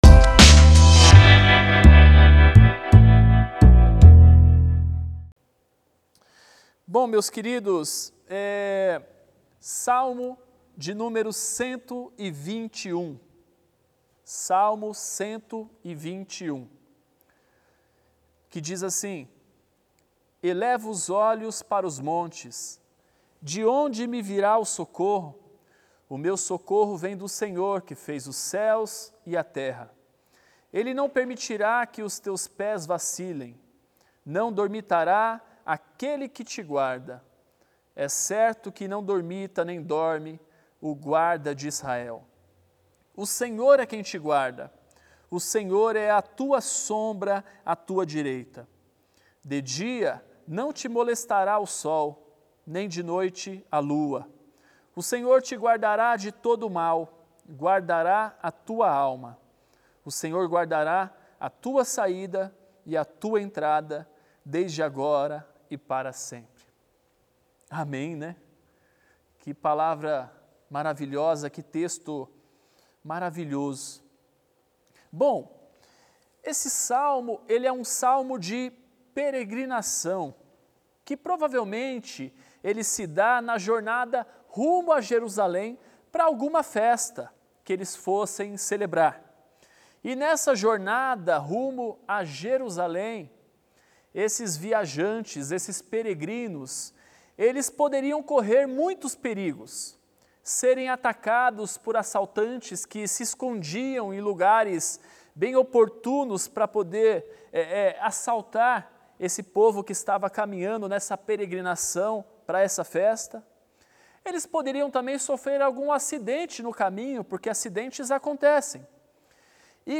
Mensagens